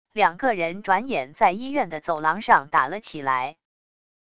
两个人转眼在医院的走廊上打了起来wav音频生成系统WAV Audio Player